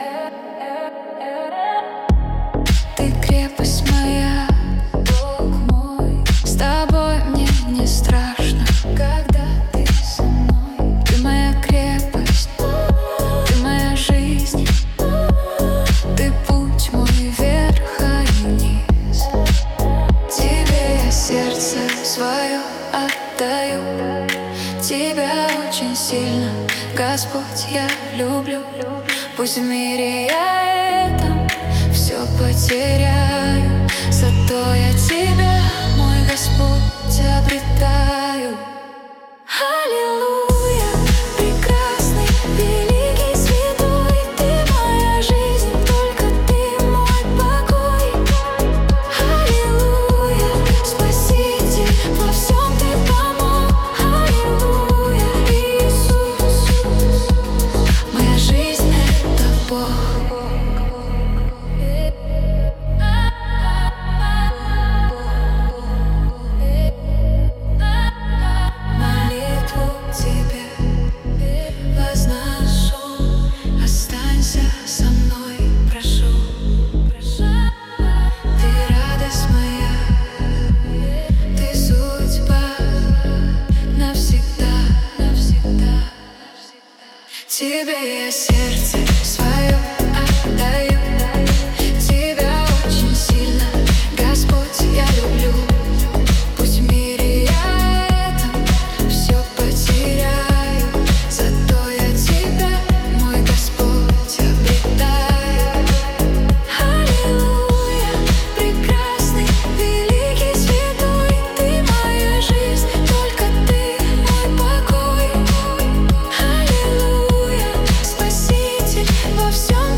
песня ai
11 просмотров 16 прослушиваний 3 скачивания BPM: 99